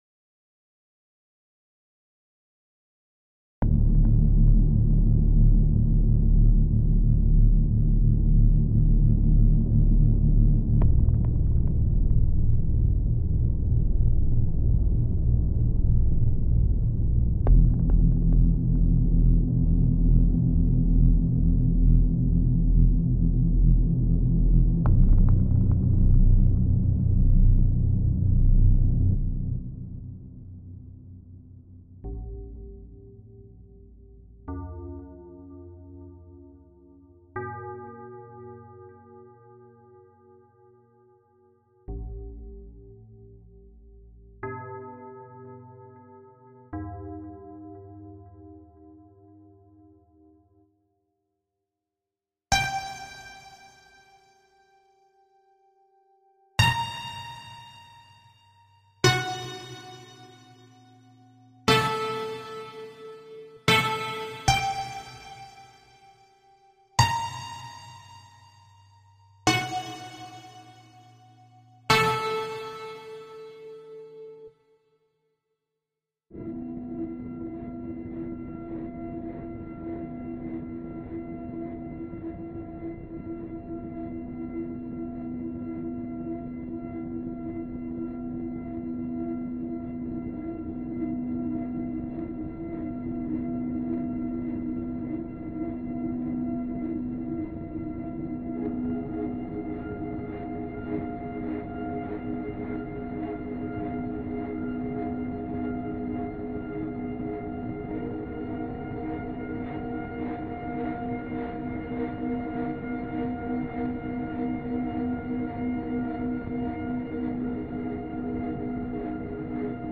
Ну и вот пример хоррор-синтезаторов, звуки которых можно использовать для фоновой озвучки:
horrorsounds.mp3